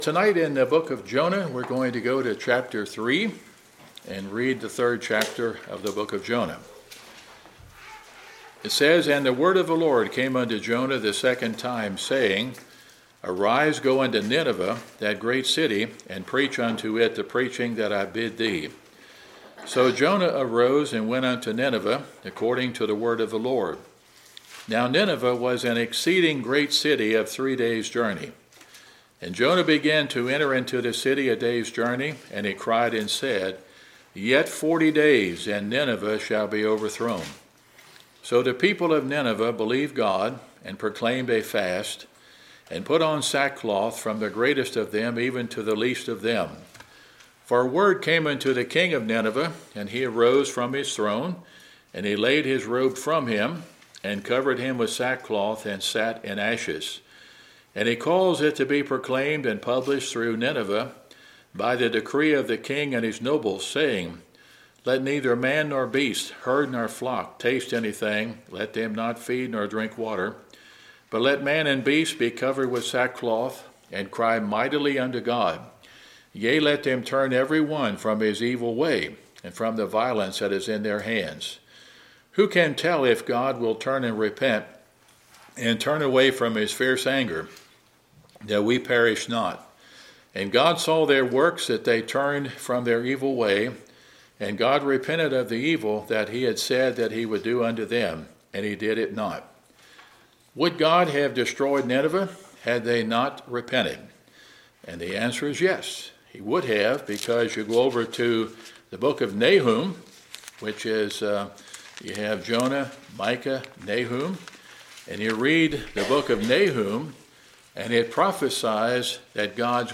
Wednesday Sermon Book